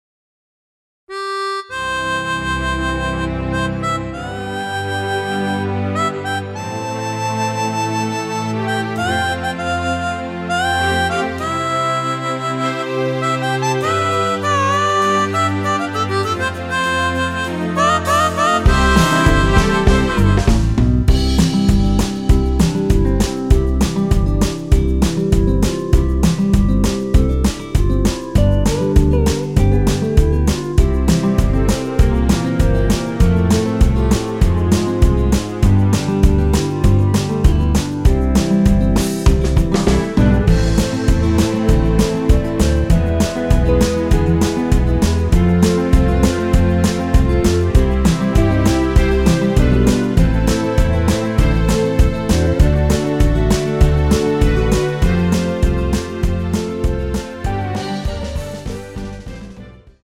전주가 길어서 8마디로 편곡 하였으며
원키(1절+후렴)으로 진행되는 멜로디 포함된 MR입니다.
앞부분30초, 뒷부분30초씩 편집해서 올려 드리고 있습니다.
중간에 음이 끈어지고 다시 나오는 이유는